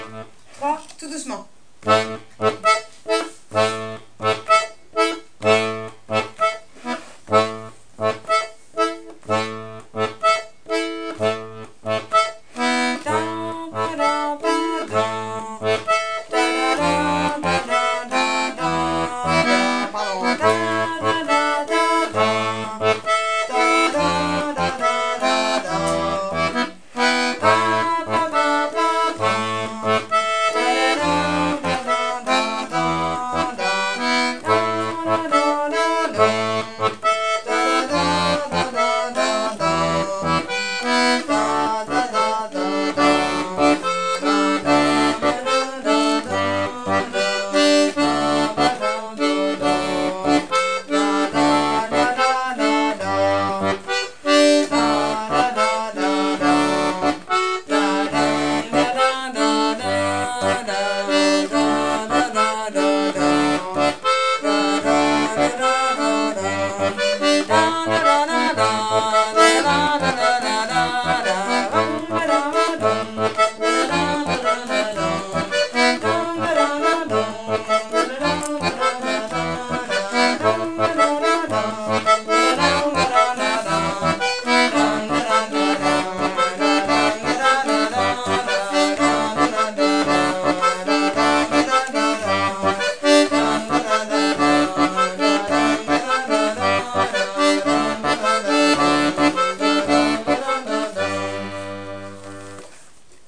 l'atelier d'accordéon diatonique
Bonus à découvrir: bourrée 2t la bergere de Coulandon
rythmique